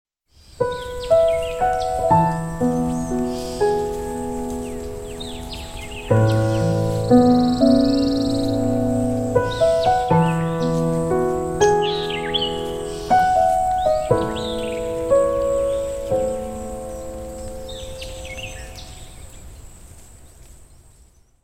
weather_alarm_sun.ogg